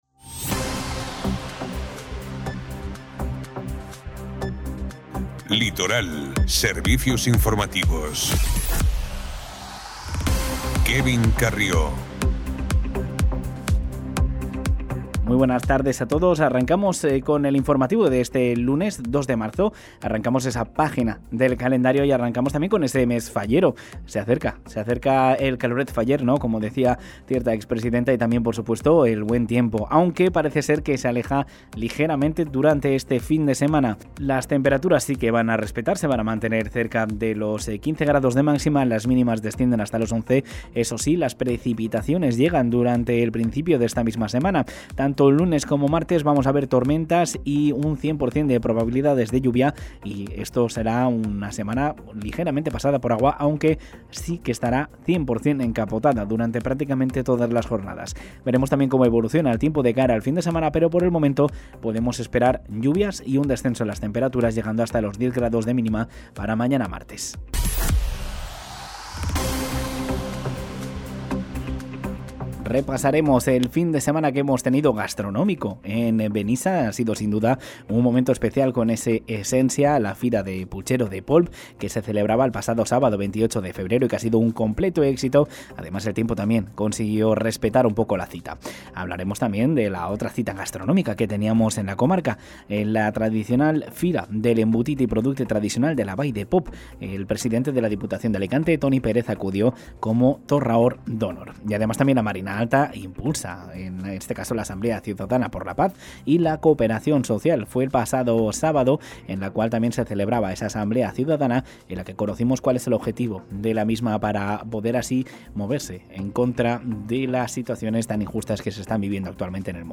Informativo Ràdio Litoral 02/03/2026 | Ràdio Litoral